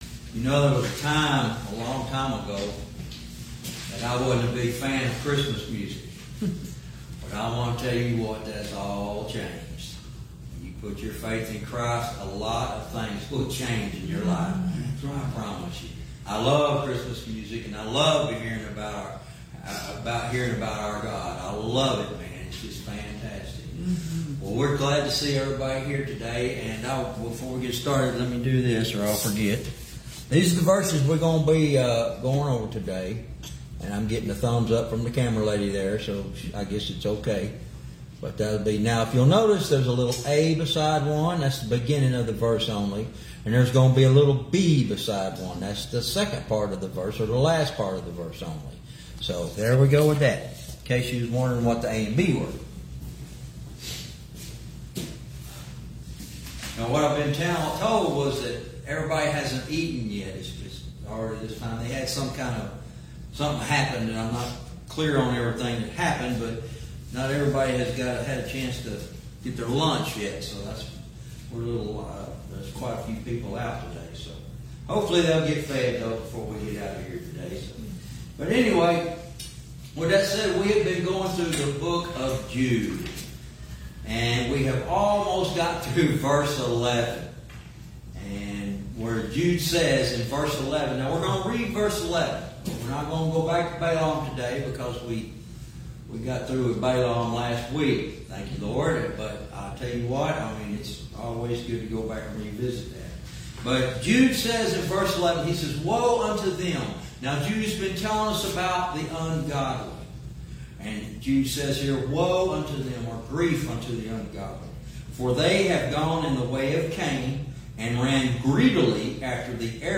Verse by verse teaching - Lesson 44 Jude 1:11